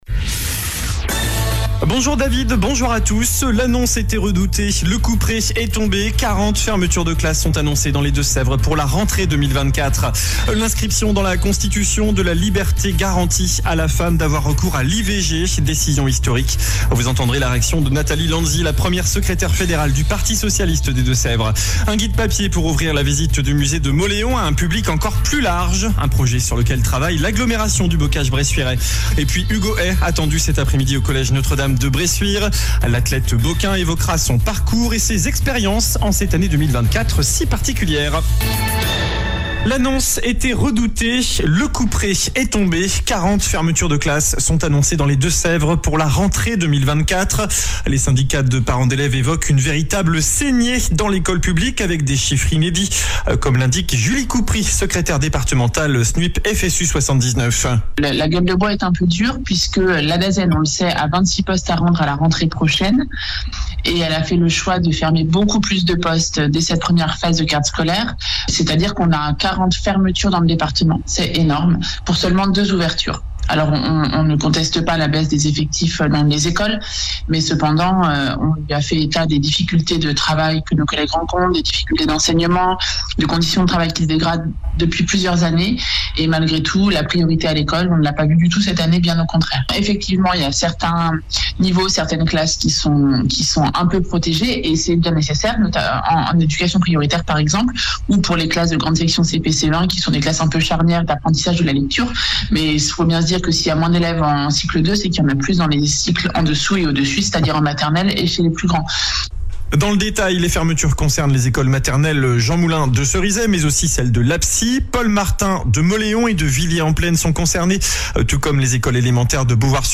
JOURNAL DU MARDI 05 MARS ( MIDI )